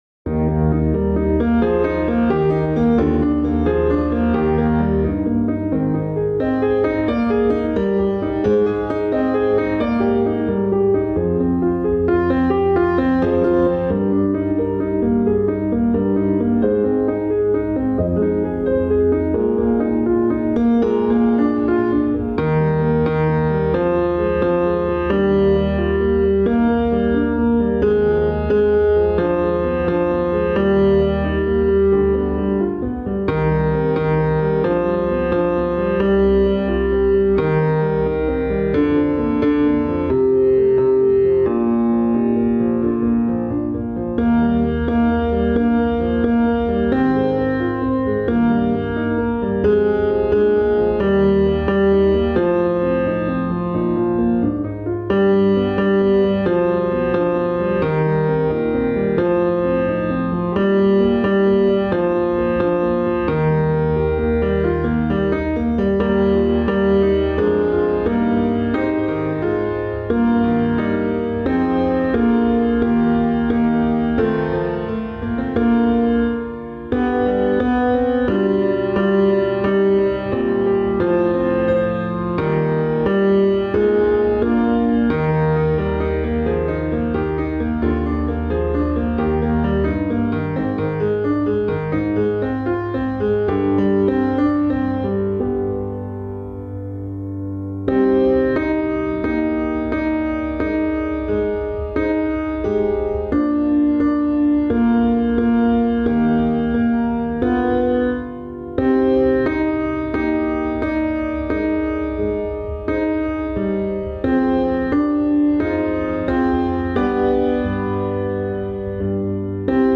Like a River Glorious – Tenor